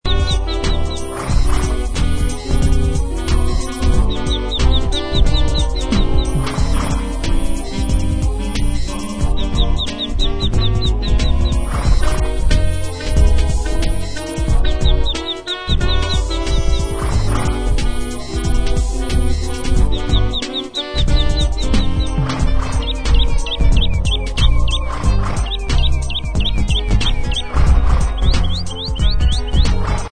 alouettes, cheval, cigales, canneton